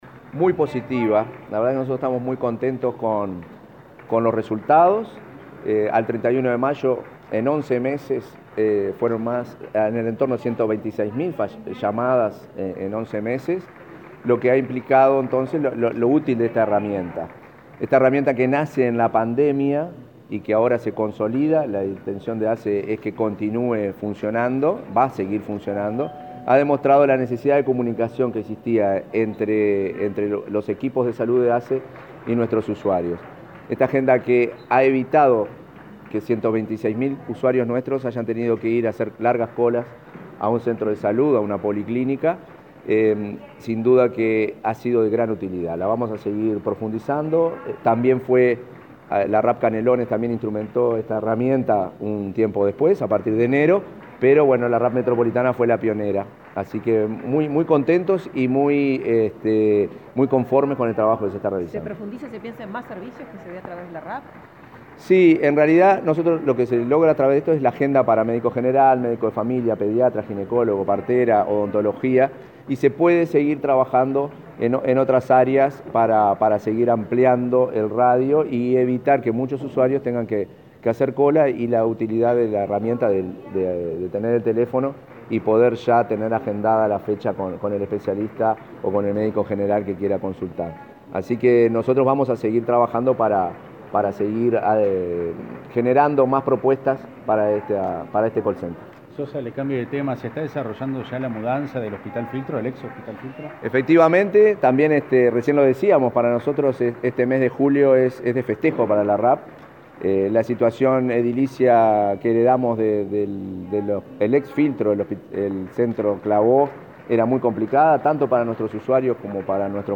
Declaraciones a la prensa del presidente interino de ASSE, Marcelo Sosa